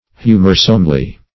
humorsomely - definition of humorsomely - synonyms, pronunciation, spelling from Free Dictionary Search Result for " humorsomely" : The Collaborative International Dictionary of English v.0.48: Humorsomely \Hu"mor*some*ly\, adv. Pleasantly; humorously.